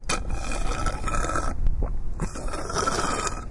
描述：这是一段关于某人喝完自来水杯中最后一点健怡百事可乐和冰块的录音。 我是在斯坦福大学的Coho餐厅用Roland Edirol录制的。